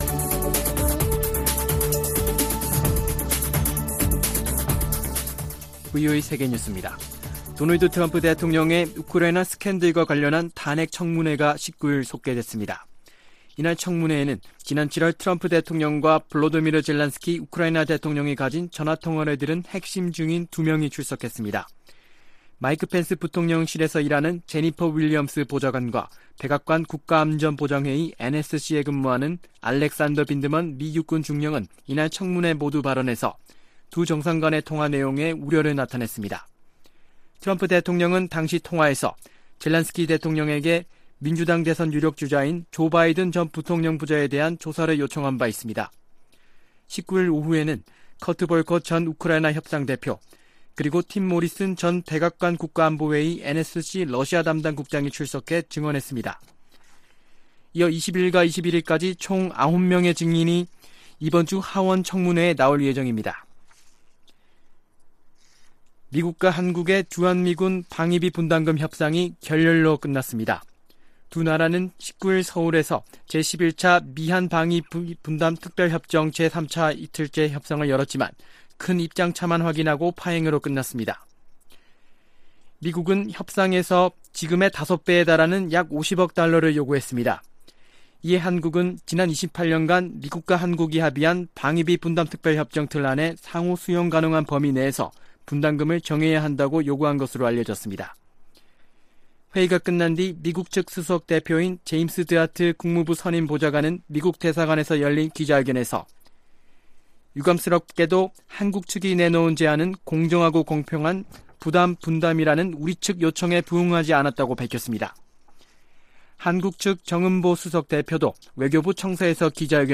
VOA 한국어 아침 뉴스 프로그램 '워싱턴 뉴스 광장' 2018년 11월 20일 방송입니다. 최근 북한이 적대시 정책을 철회 하라는 내용의 미국을 향한 성명을 잇따라 발표하고 있습니다. 미국과 한국의 방위비 분담금 협상이 결렬로 끝났습니다.